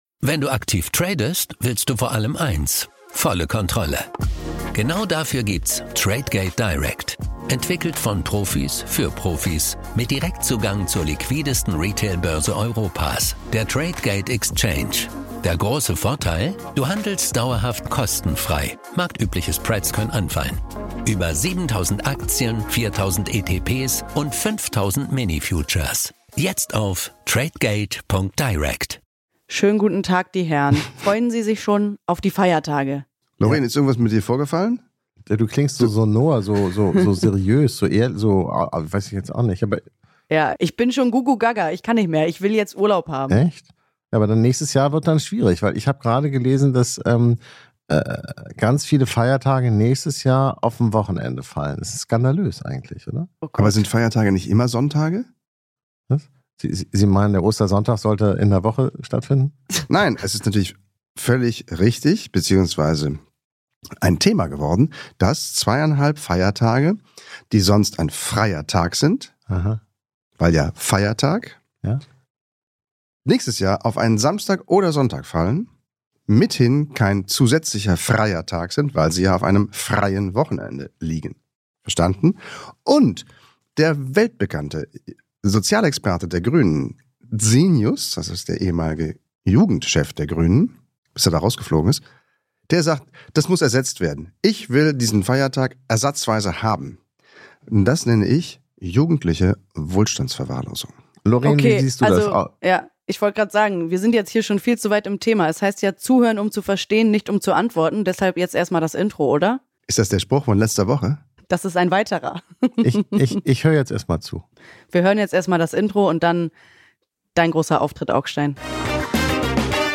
Augstein & Blome diskutieren darüber in der heutigen Folge und offenbaren sich als Weihnachtsmarkt-Hater.